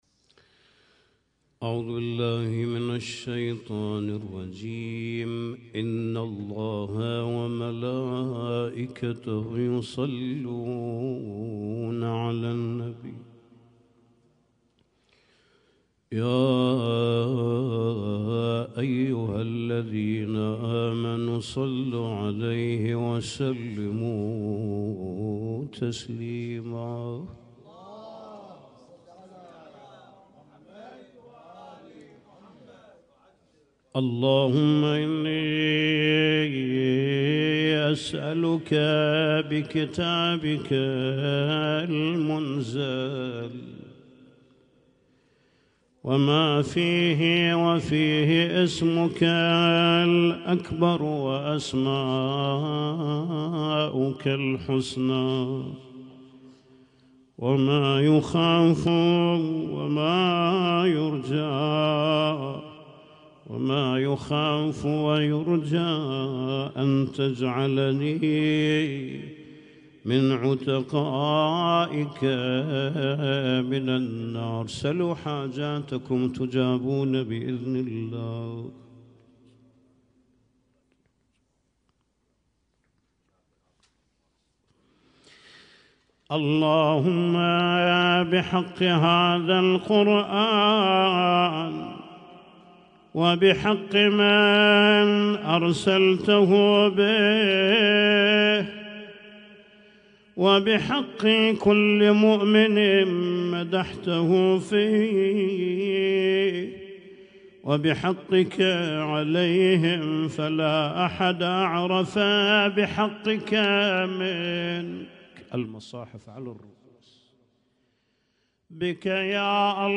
Husainyt Alnoor Rumaithiya Kuwait
اسم التصنيف: المـكتبة الصــوتيه >> الادعية >> ادعية ليالي القدر